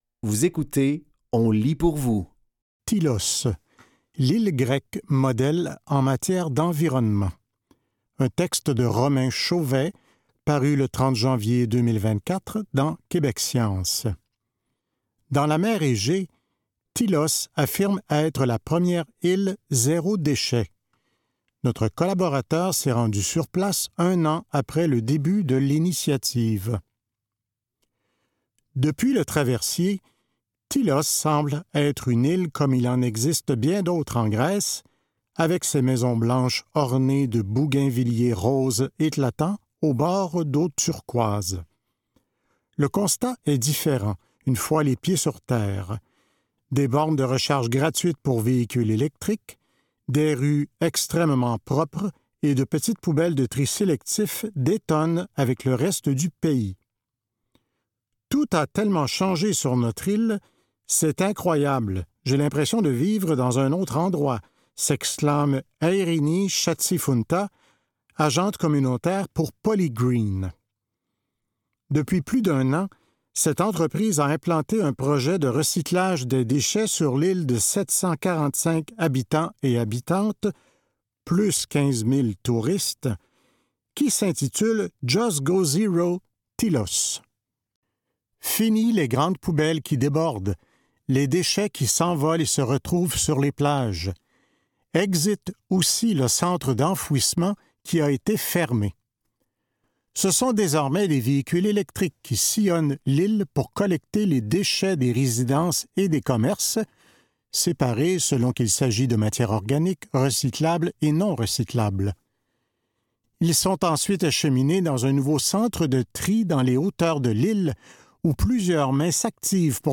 Dans cet épisode de On lit pour vous, nous vous offrons une sélection de textes tirés des médias suivants : Québec Science.